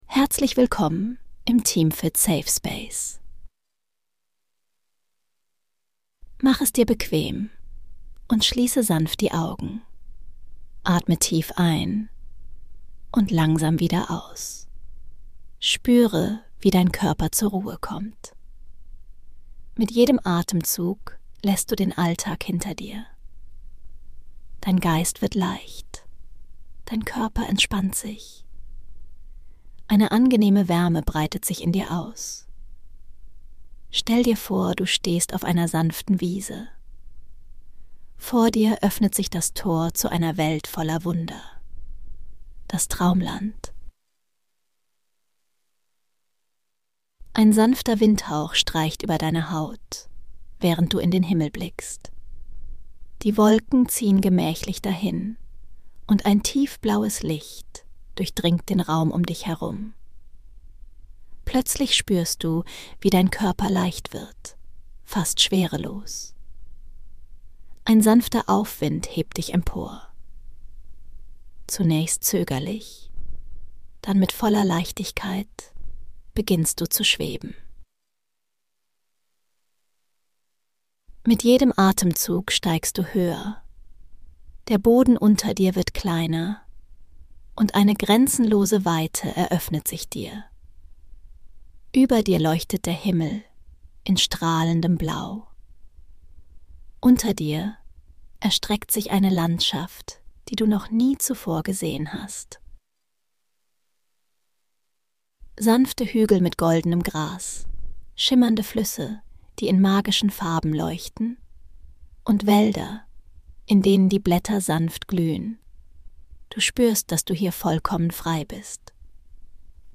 geführten Meditation schwebst du über glitzernde Landschaften,